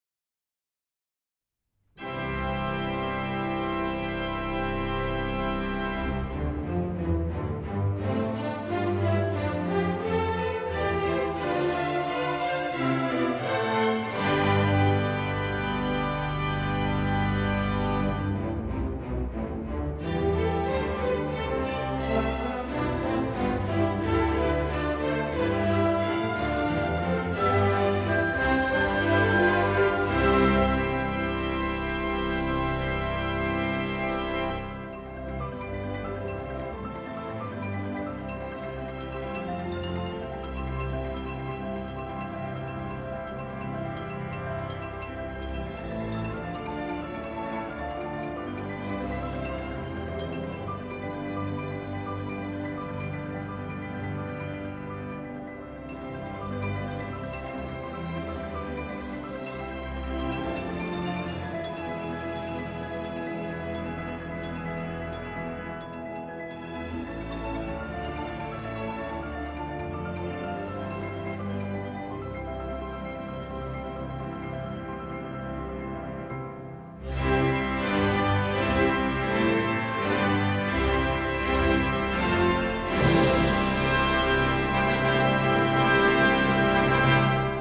saint-saens-symphony-no-3-organ-mehta-berliner-philharmoniker-audiotrimmer-com.mp3